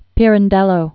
(pîrən-dĕlō, pērän-dĕllō), Luigi 1867-1936.